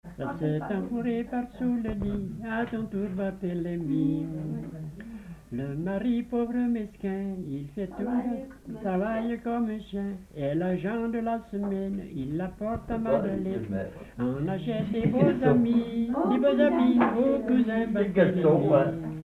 Chanson